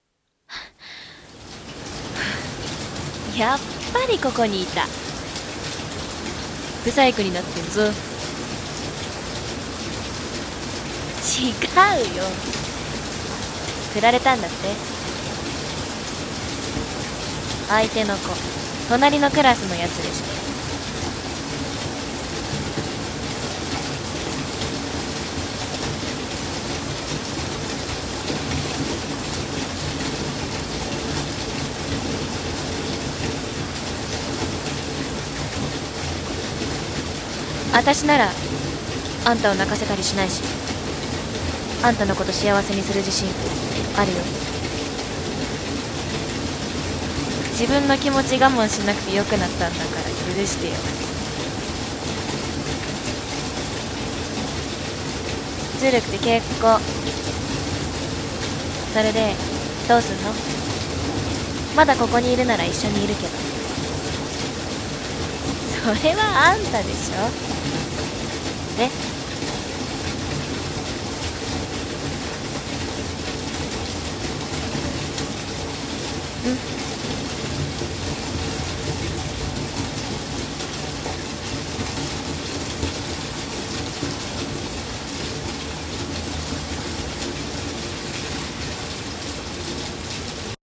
【二人声劇】涙は雨に隠して